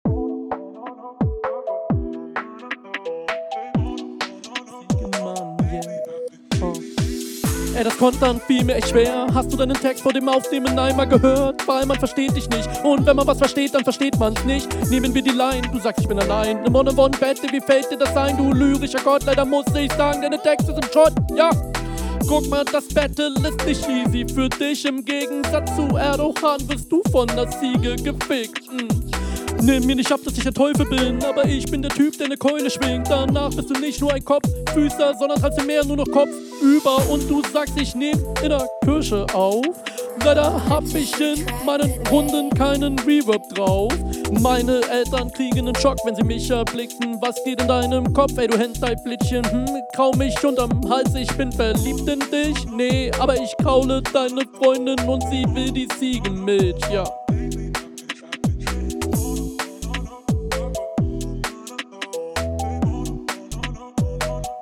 Du bist teilweise off-Offbeat.